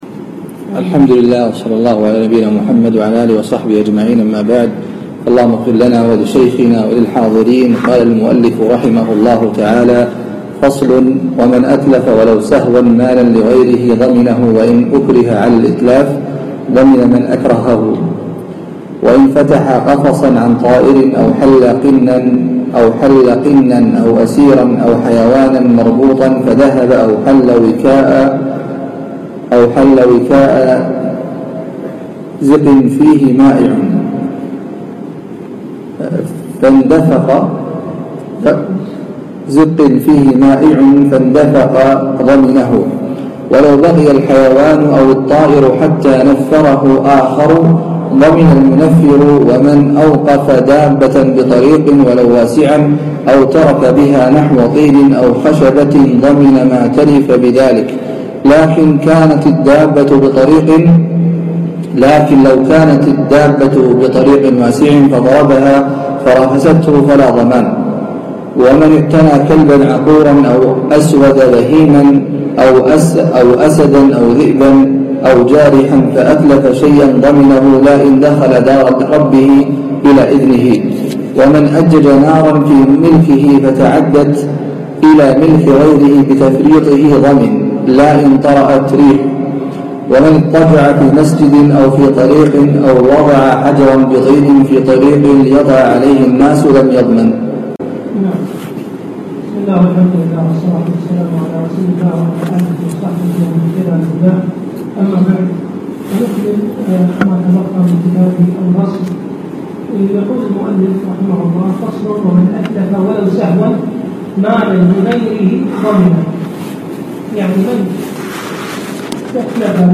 الدرس الثالث عشر - كتاب الغصب - فصل في الإتلاف